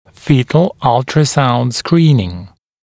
[‘fiːt(ə)l ‘ʌltrəsaund ‘skriːnɪŋ][‘фи:т(э)л ‘алтрэсаунд ‘скри:нин]ультразвуковое исследование плода